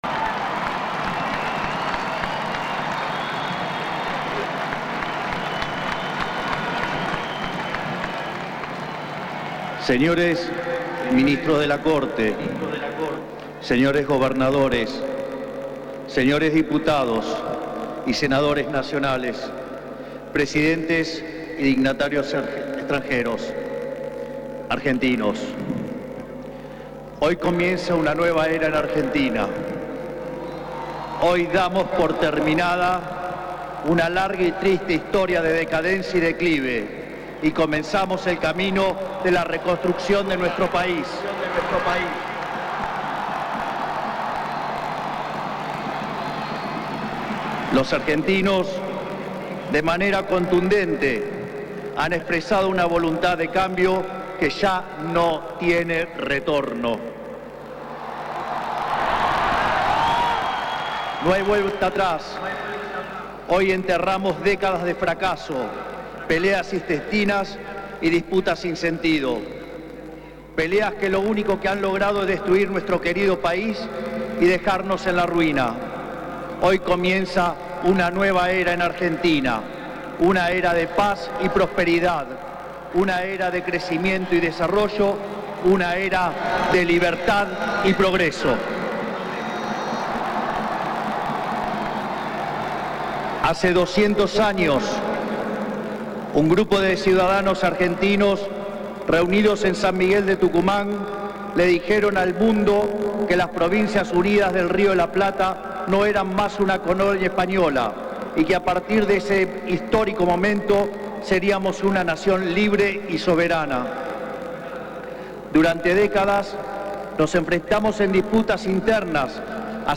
«Hola a todos…», comenzó diciendo el libertario emulando la música que utilizó en su campaña ante la multitud que lo esperaba frente al congreso tras jurar y convertirse oficialmente en el nuevo Presidente de la Nación.
TAP-DISCURSO-MILEY.mp3